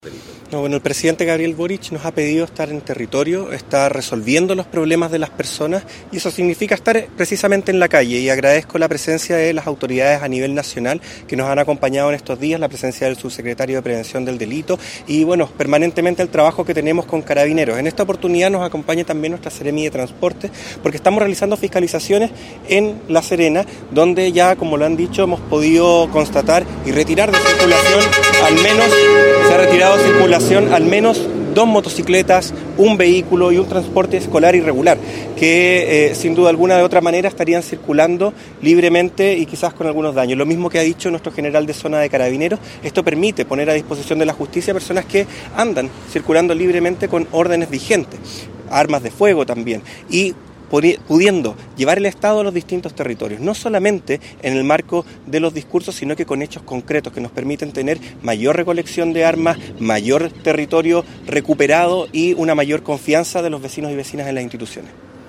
Para el Delegado Presidencial Regional, Rubén Quezada,
FISCALIZACION-VEHICULAR-Delegado-Presidencial-Ruben-Quezada.mp3